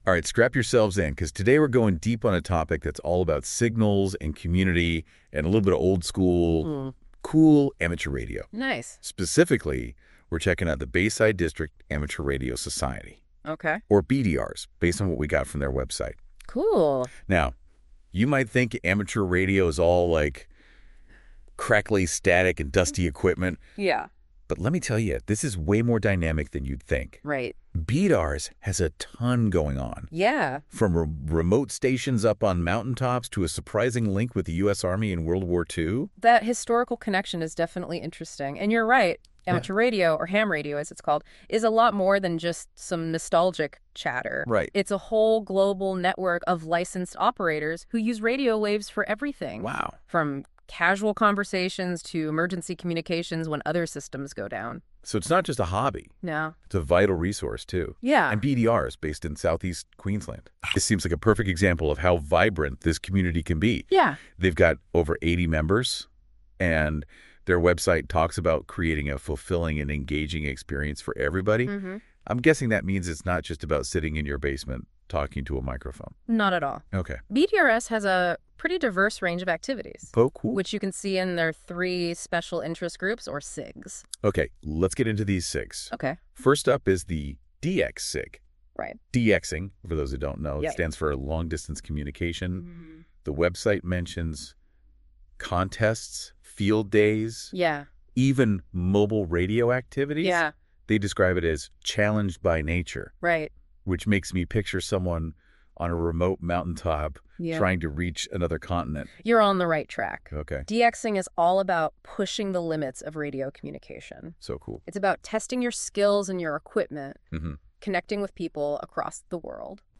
Listen to a podcast about us generated by an AI (Thanks to Google’s Notebook LM) Centred in Brisbane’s beautiful bayside area, our meeting venue is the Redland Museum in Cleveland.